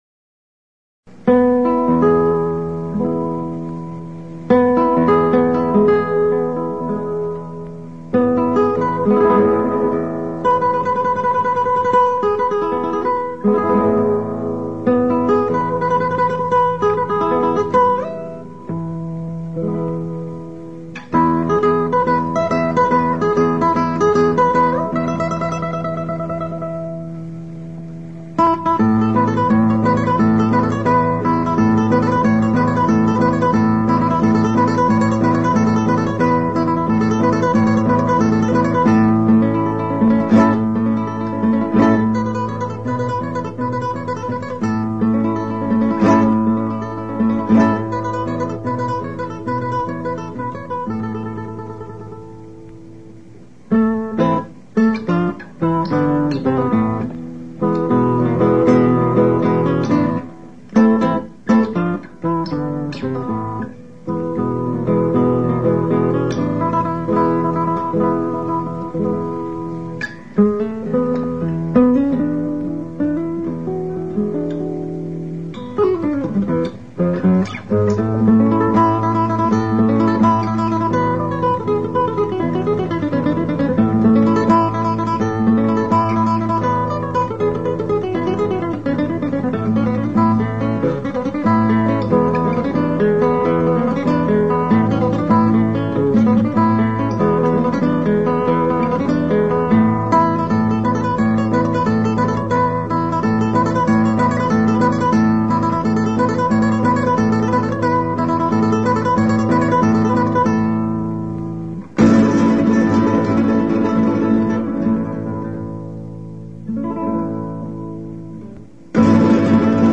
Classical Guitar Kresge Little Theater MIT Cambridge, Massachusetts USA March 22